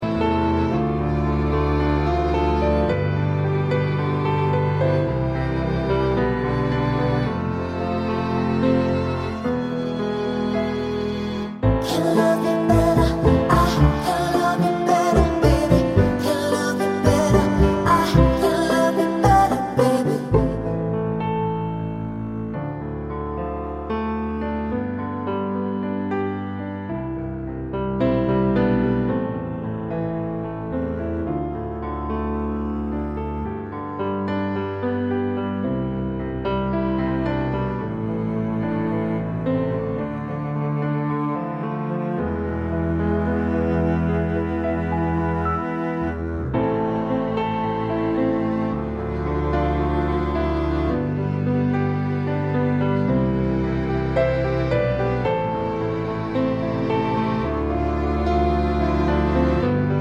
Original Female Key with No Backing Vocals